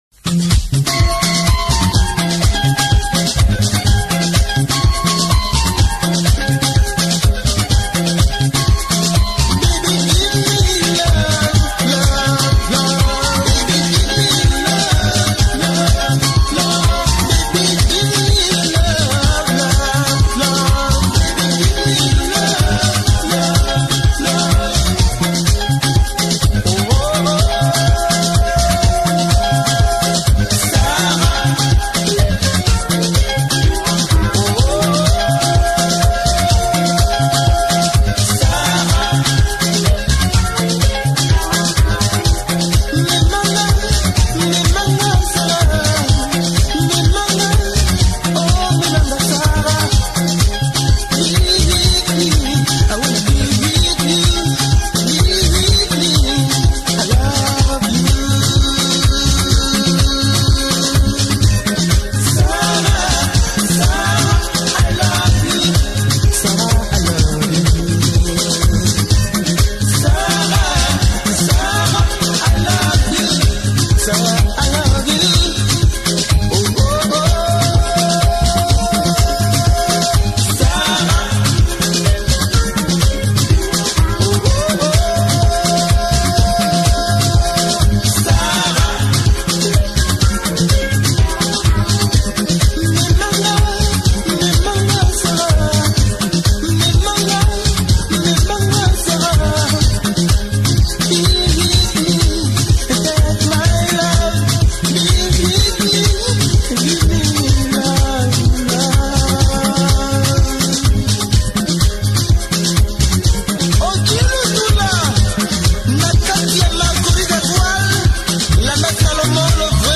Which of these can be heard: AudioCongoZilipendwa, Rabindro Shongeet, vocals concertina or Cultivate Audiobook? AudioCongoZilipendwa